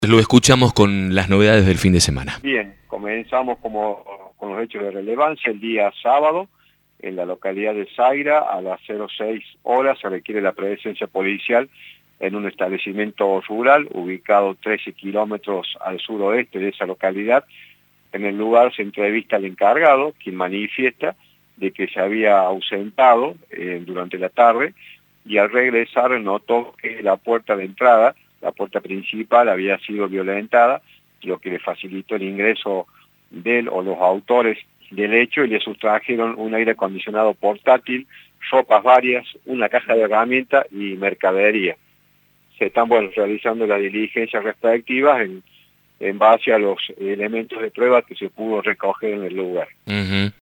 Está al sur de Saira, Escuchá el informe.